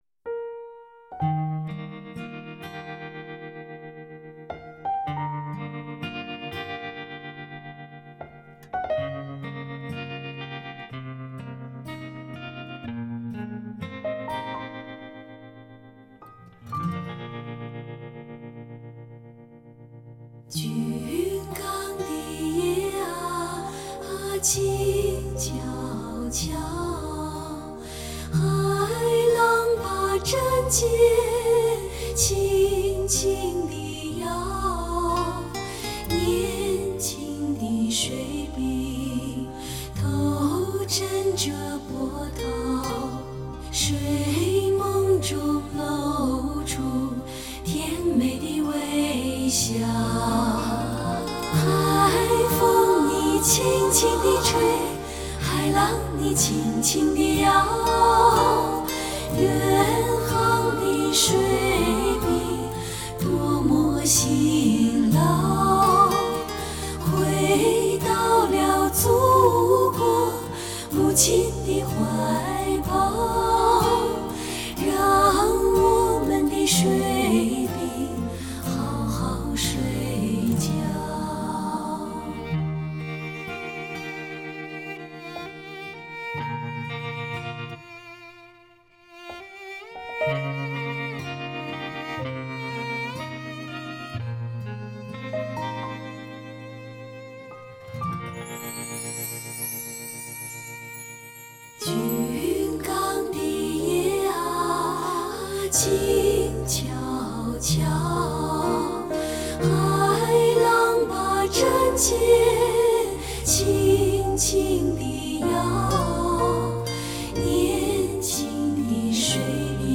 令人屏息的天籁质感 始终弥漫至每一个音符